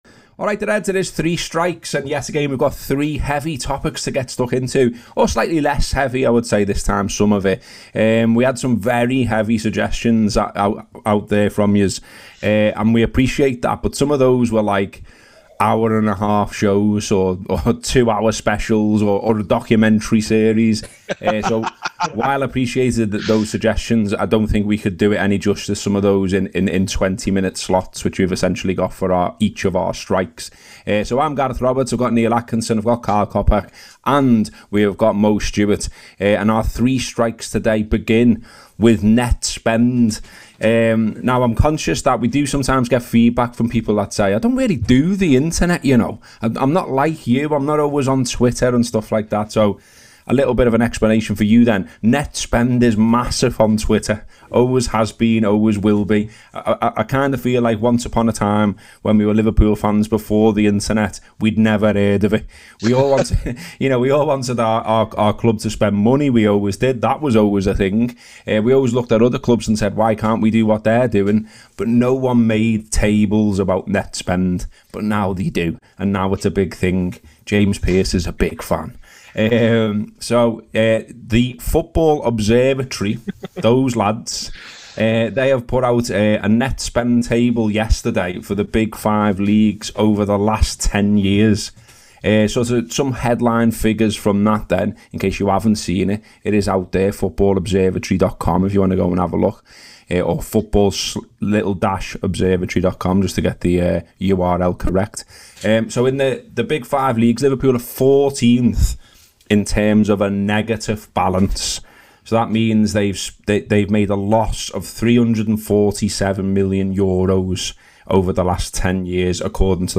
The three talking points the panel discuss are the net spend table, criticism of Dele Alli and the latest twist in the Manchester United saga.